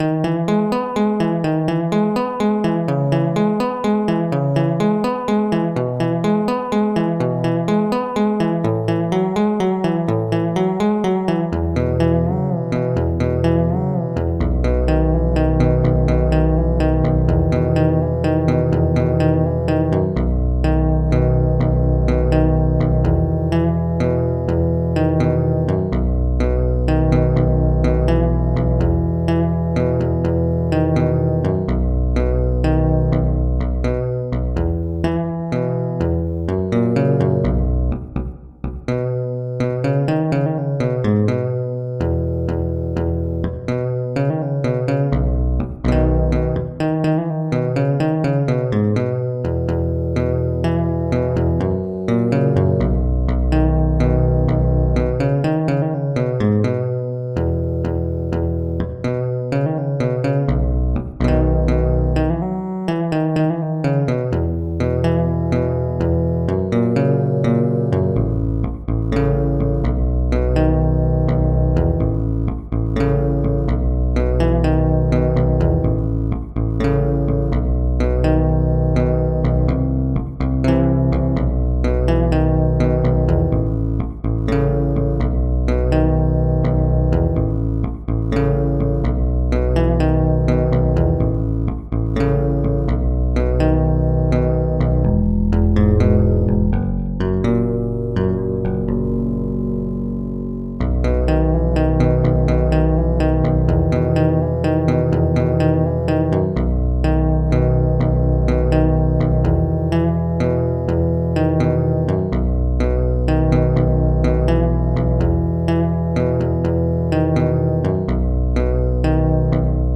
'Joonas' for solo bass guitar
This is a relaxed song made using bass guitar samples, designed to be playable on a real bass guitar.
So I settled for "synthesizer" since it isn't played on a real bass.
joonas-forsolobass.mp3